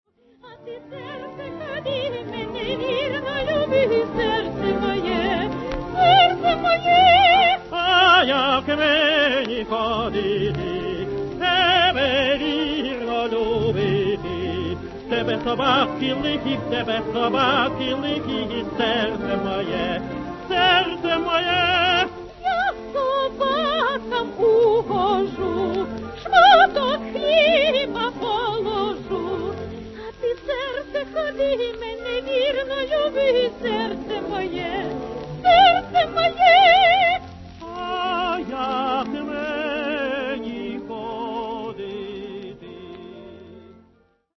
Украинскую песню «Дiвка в сiнях стояла» они исполняют здесь вместе —